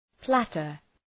Προφορά
{‘plætər}